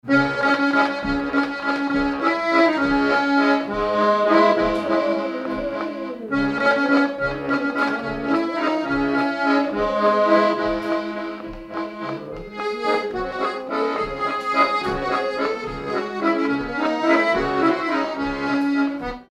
Mémoires et Patrimoines vivants - RaddO est une base de données d'archives iconographiques et sonores.
Valse
danse : valse
circonstance : bal, dancerie
Pièce musicale inédite